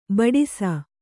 ♪ baḍisa